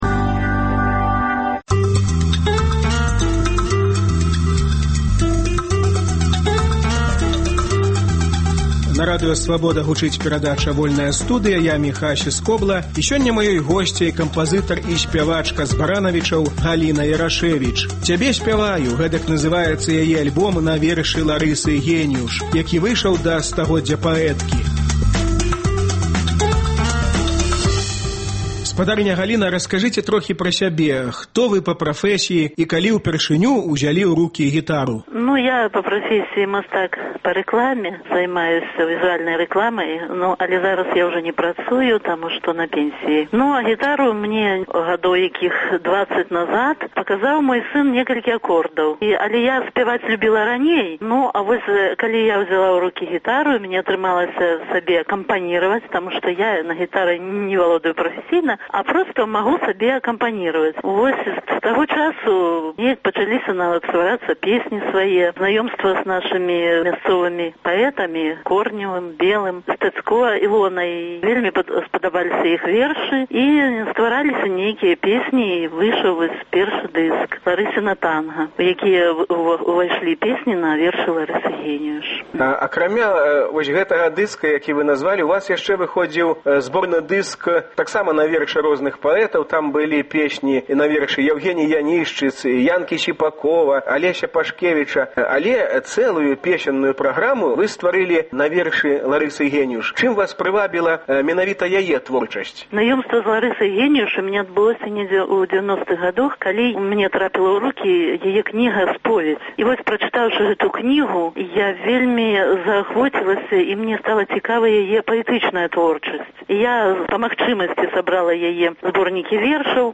Гутарка з кампазытаркай і выканаўцай з Баранавічаў.